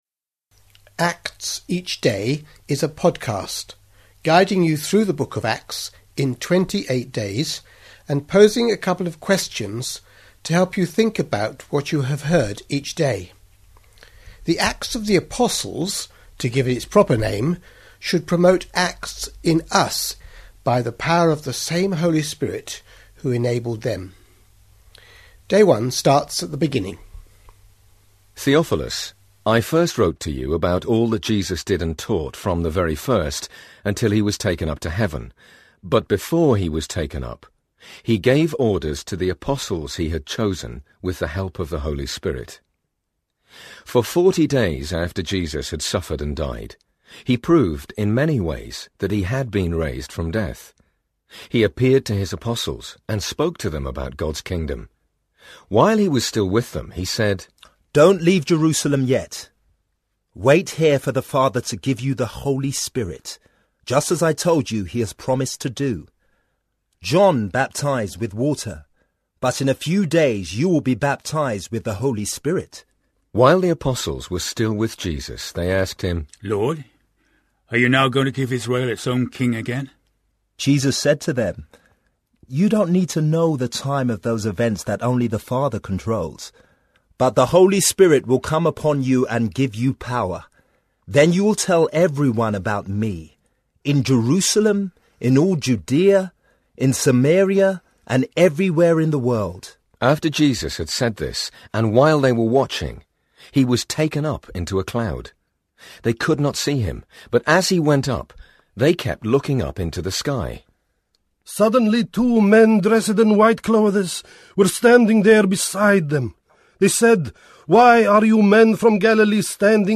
A daily reading from the book of Acts for July 2012